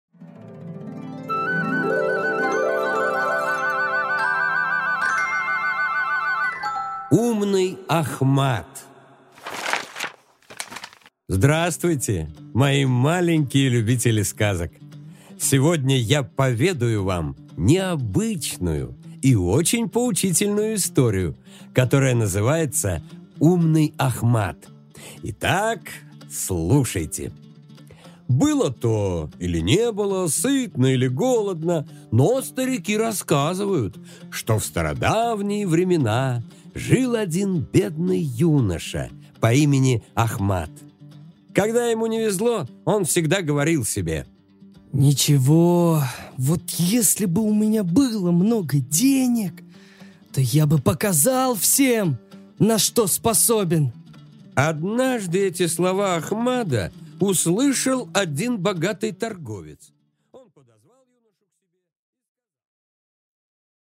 Аудиокнига Умный Ахмад